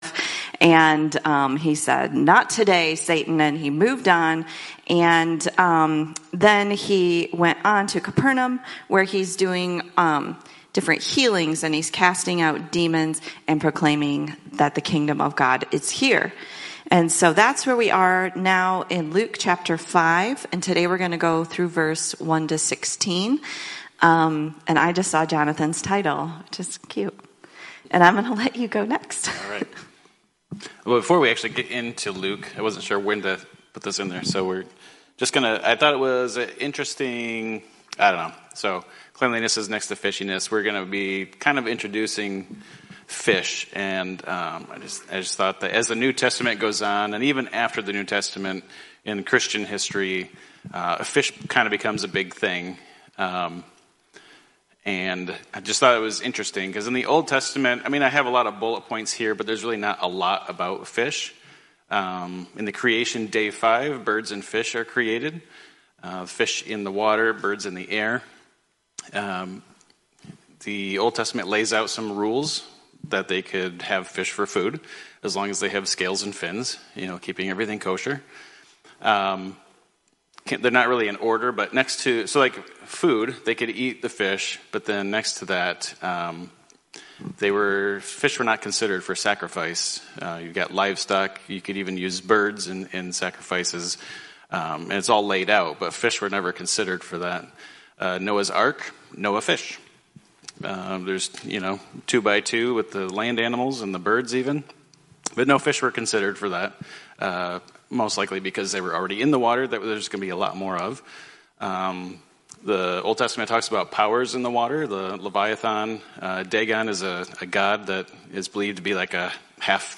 10/20/24 Sunday AM Luke 5:1-16